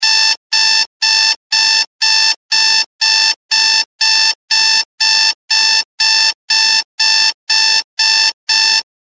دانلود صدای زنگ 1 از ساعد نیوز با لینک مستقیم و کیفیت بالا
جلوه های صوتی